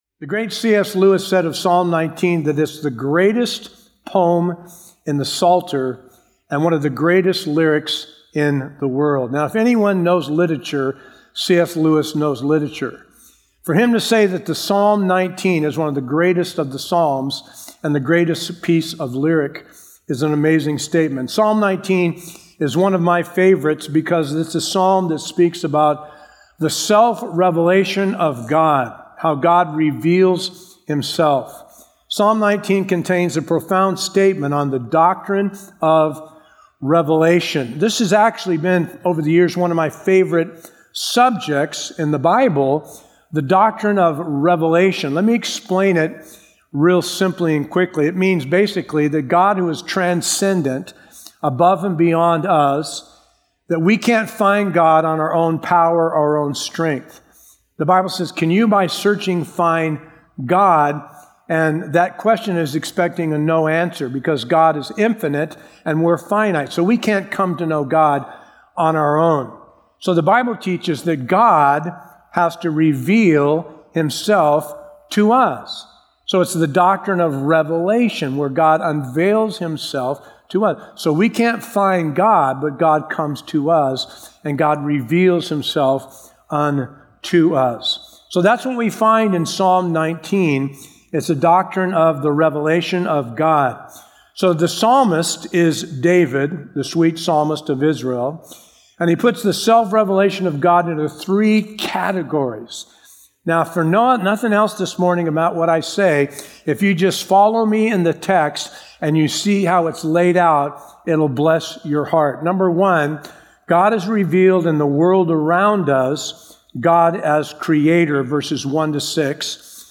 A verse-by-verse expository sermon through Psalms 19